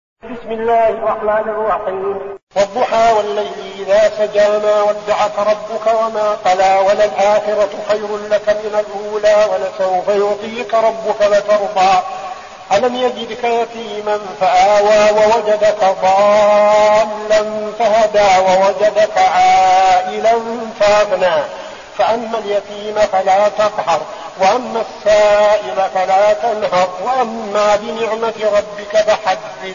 المكان: المسجد النبوي الشيخ: فضيلة الشيخ عبدالعزيز بن صالح فضيلة الشيخ عبدالعزيز بن صالح الضحى